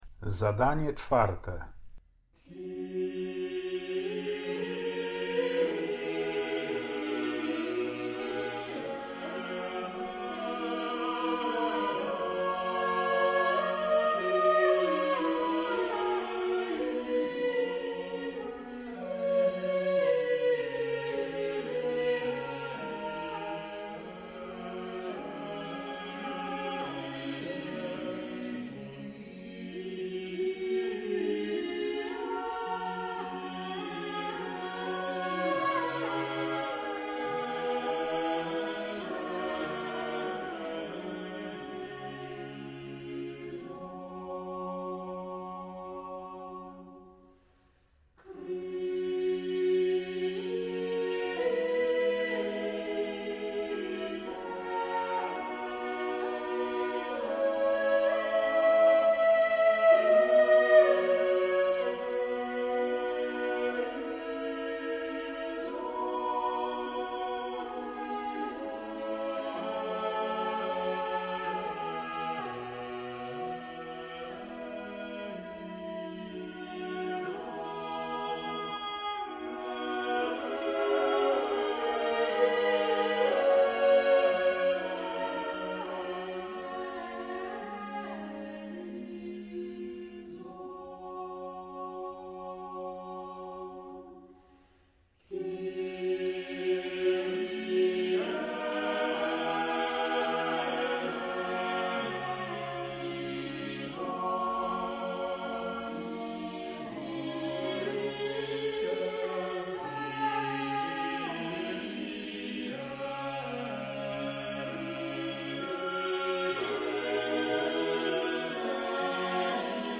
Johannes Ockeghem, Msza L’homme armé, Kyrie